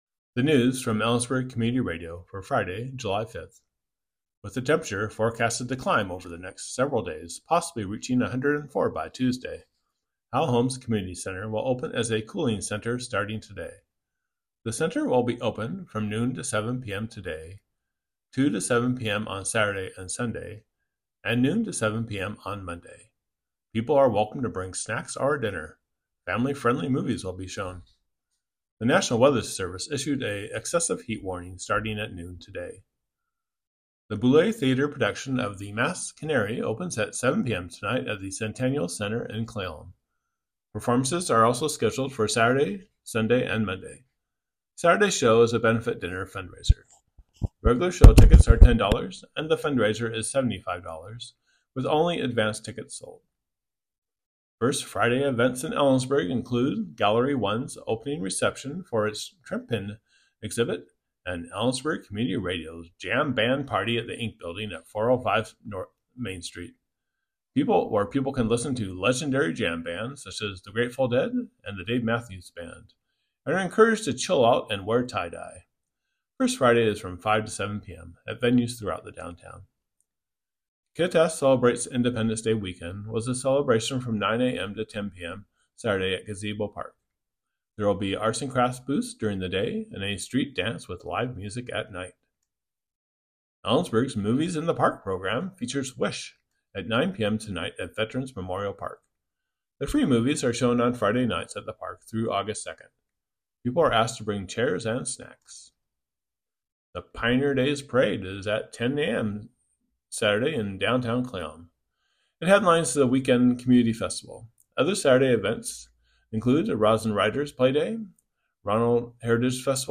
Click here to listen to today's newscast